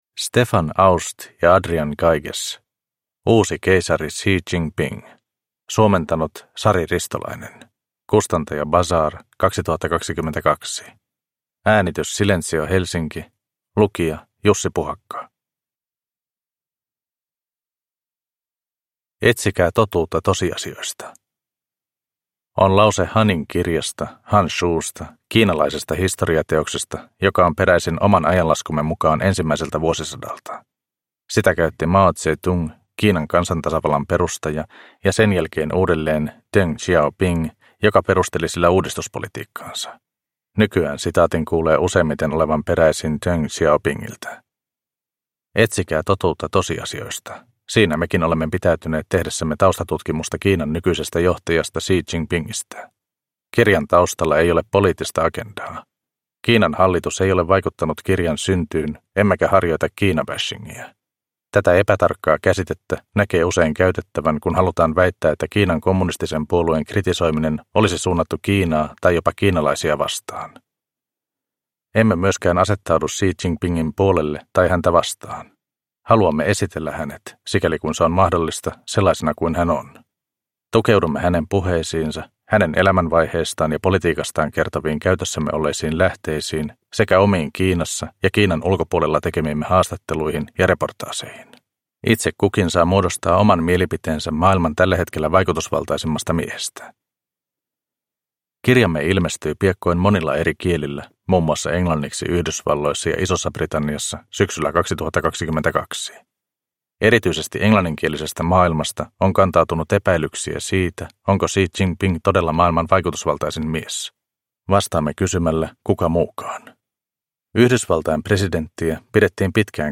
Uusi keisari Xi Jinping – Ljudbok – Laddas ner